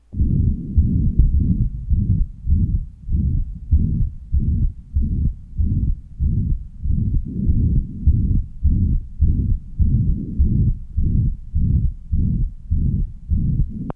Date 1973 Type Systolic Abnormality Ventricular Septal Defect and Pulmonary Stenosis VSD with valvar and infundibular PS, ejection click appears on expiration To listen, click on the link below.